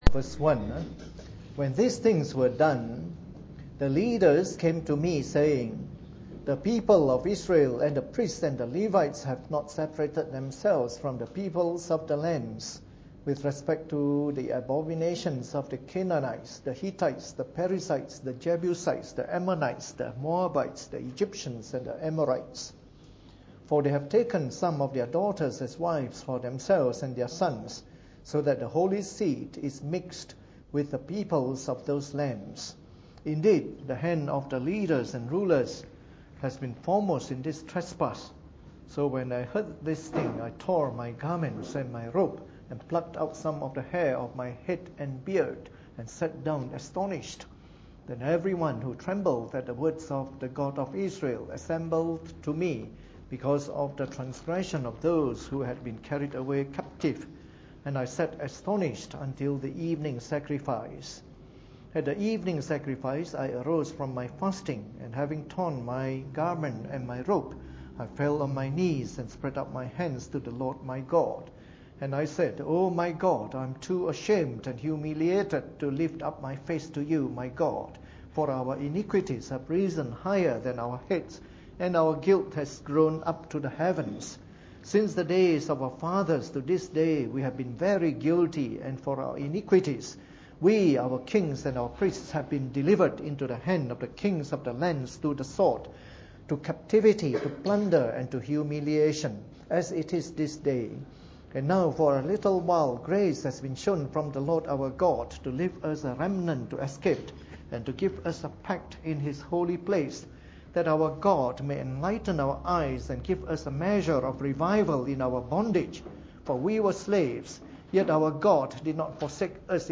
Preached on the 19th of March 2014 during the Bible Study, from our series of talks on the Book of Ezra.